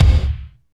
35.03 KICK.wav